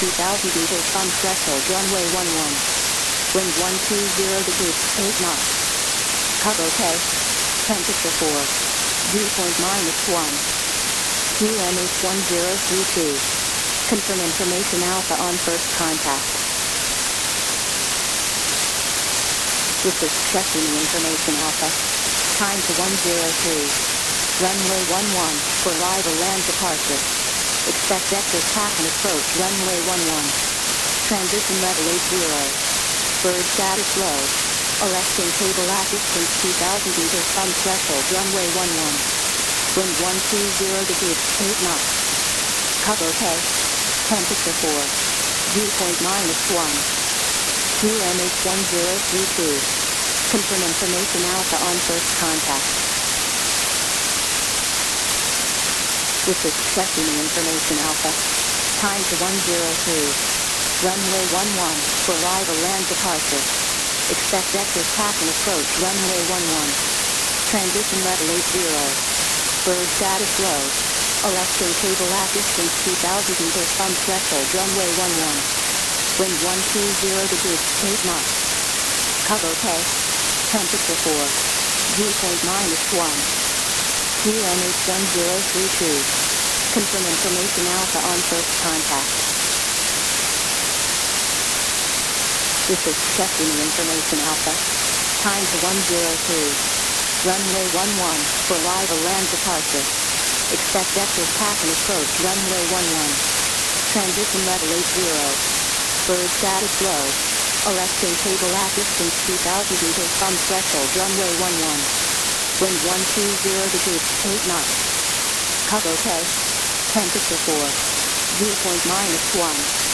Hm, trafiłem na ciekawą rzecz na 128.7 mHz, modulacja AM, Poznań. Może ktoś wie co to jest?